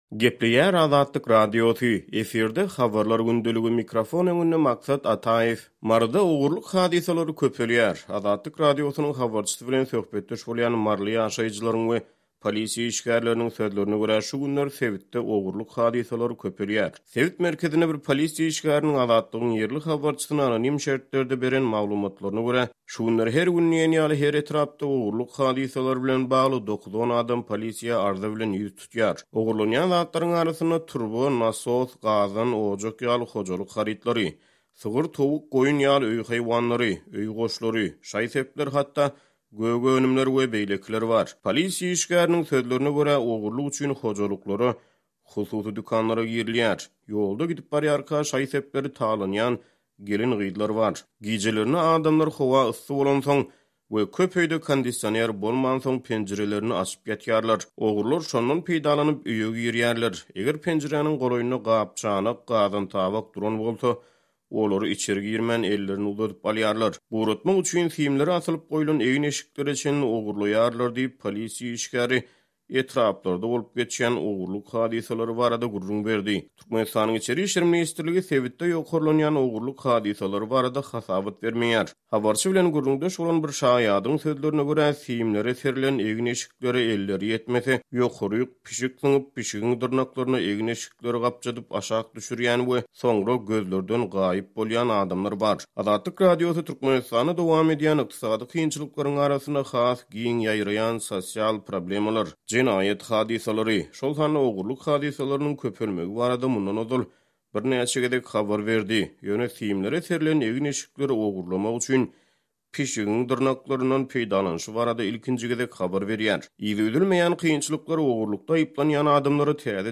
Azatlyk Radiosynyň habarçysy bilen söhbetdeş bolýan maryly ýaşaýjylaryň we polisiýa işgärleriniň sözlerine görä, şu günler sebitde ogurlyk hadysalary köpelýär.